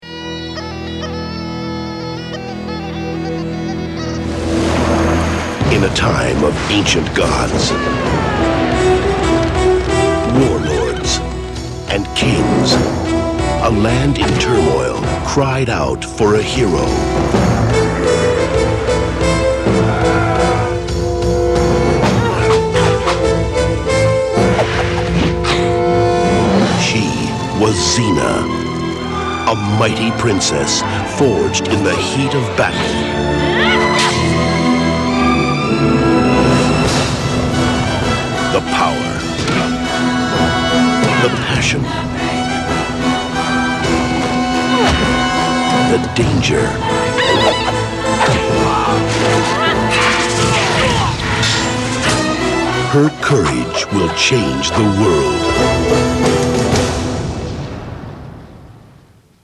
Запись с ТВ, размер 920 кб, 00:58